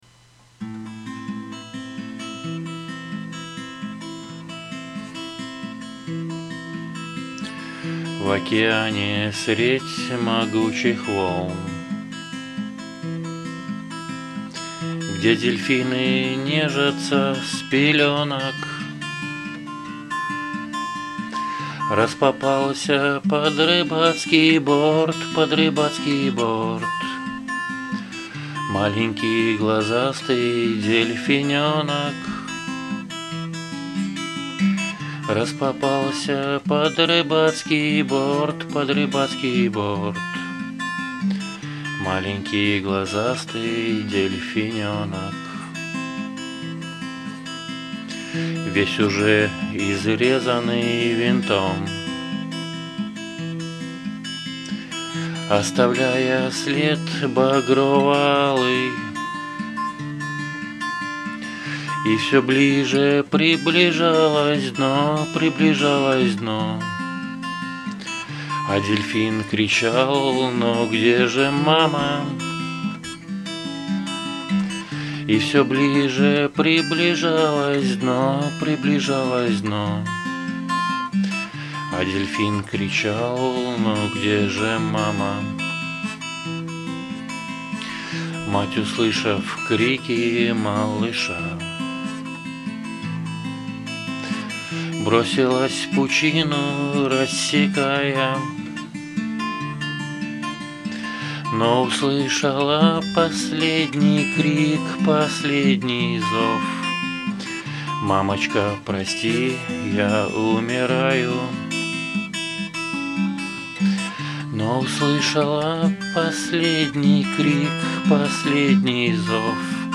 Только вот грустная песня А ты ещё из русского рока какие песни играл?